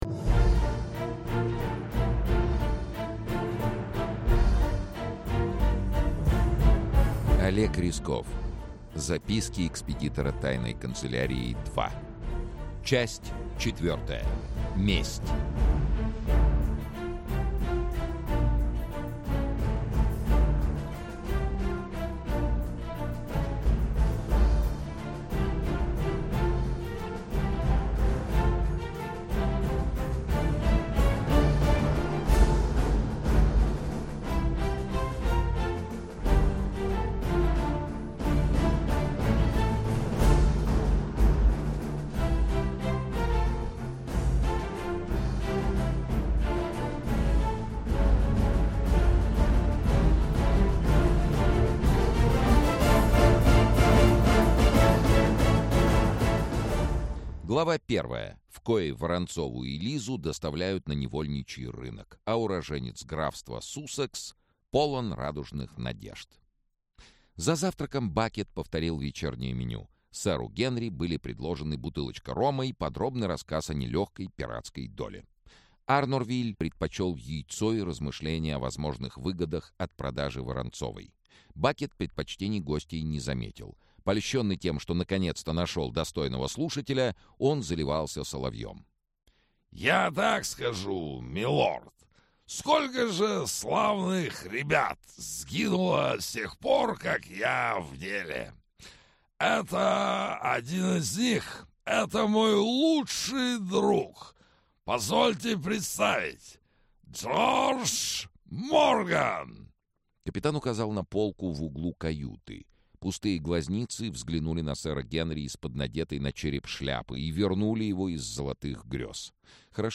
Аудиокнига К берегам Нового Света-4. Месть | Библиотека аудиокниг
Месть Автор Олег Рясков Читает аудиокнигу Сергей Чонишвили.